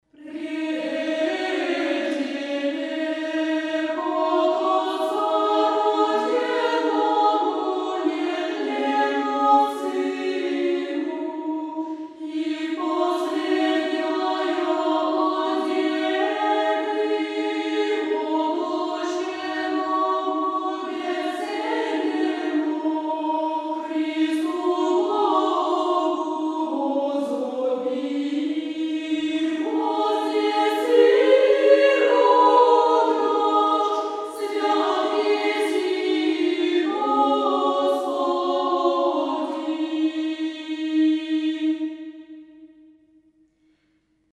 византийский распев
Канон Рождества Христова 9.mp3